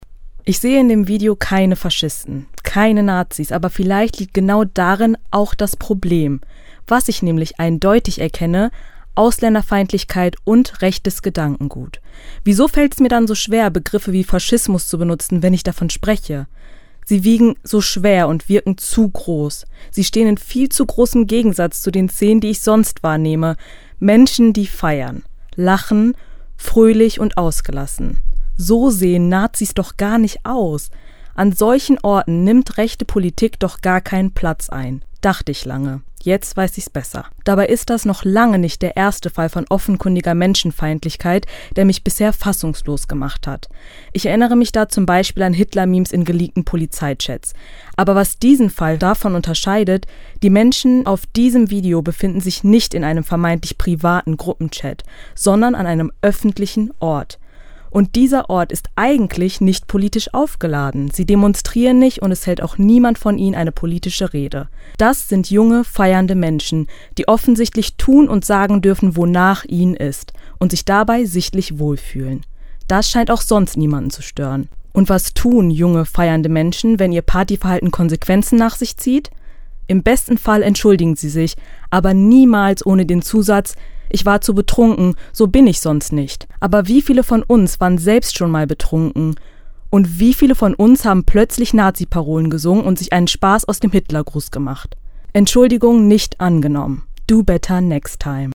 Kommentar  Ressort